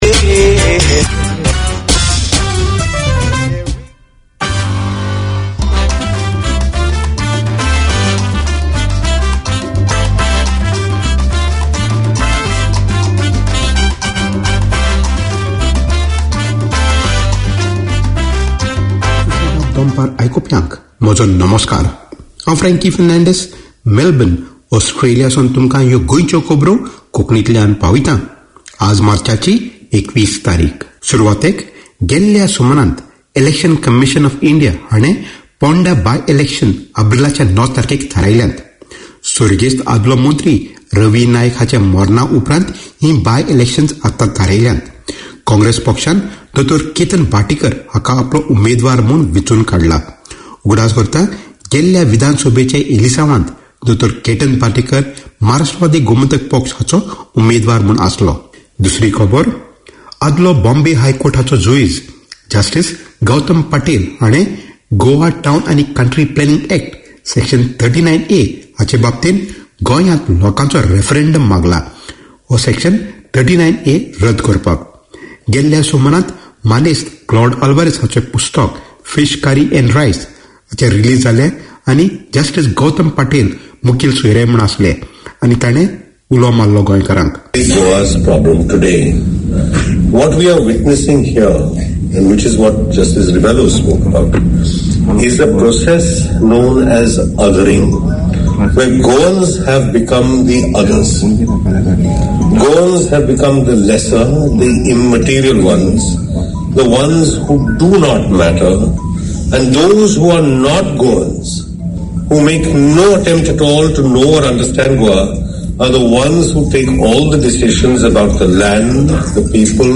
Your hosts, the Good Guys, play a rich selection of old and contemporary Konkani music, talk with local community personalities, present short radio plays, connect with community events and promote the culture and traditions.